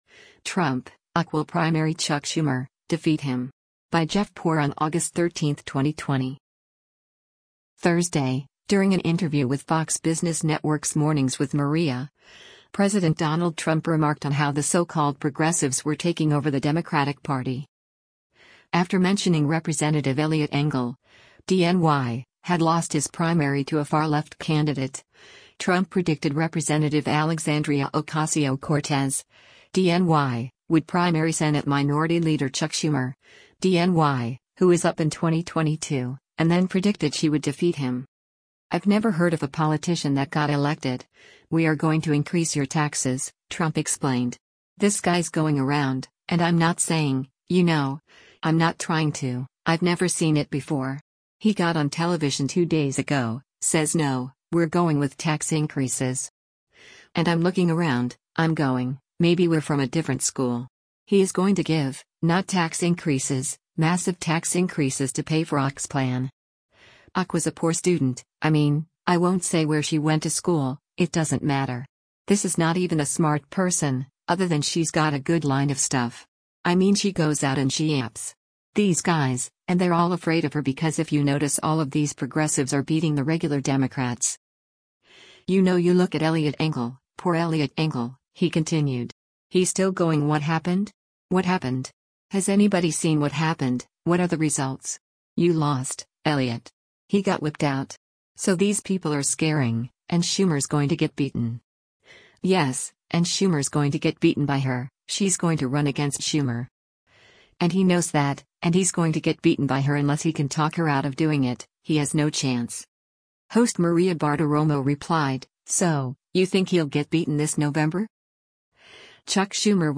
Thursday, during an interview with Fox Business Network’s “Mornings with Maria,” President Donald Trump remarked on how the so-called progressives were taking over the Democratic Party.